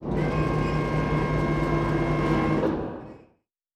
pgs/Assets/Audio/Sci-Fi Sounds/Mechanical/Servo Big 3_1.wav at master
Servo Big 3_1.wav